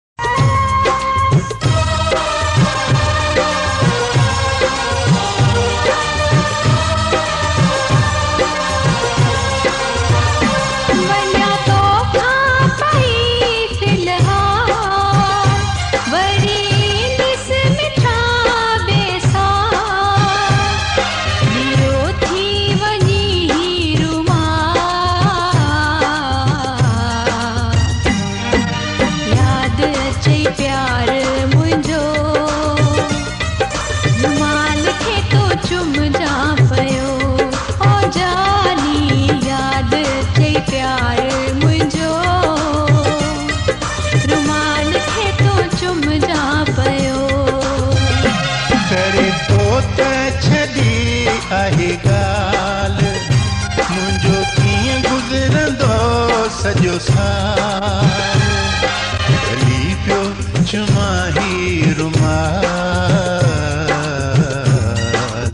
Duet Song - Old Sindhi Song Sad Filling Song